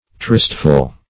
Search Result for " tristful" : The Collaborative International Dictionary of English v.0.48: Tristful \Trist"ful\ (tr[i^]st"f[.u]l), a. Sad; sorrowful; gloomy.